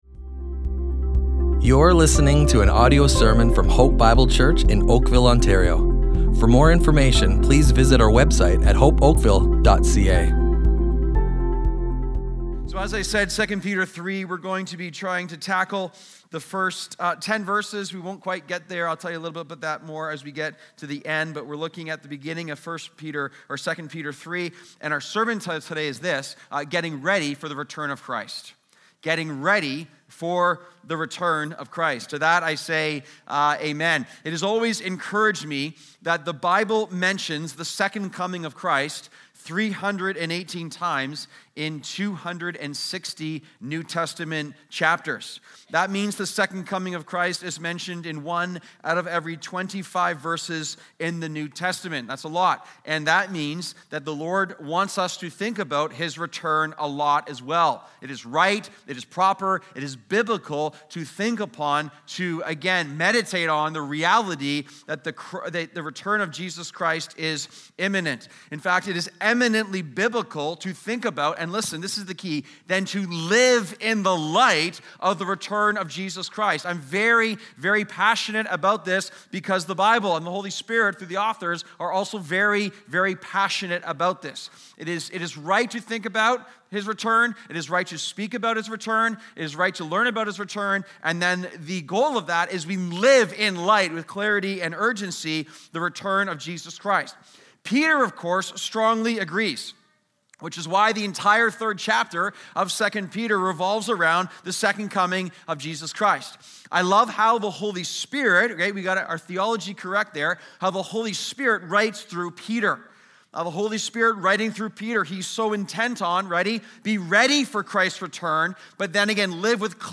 Hope Bible Church Oakville Audio Sermons Character, Corruption, and the Second Coming // Getting Ready for the Return of Christ!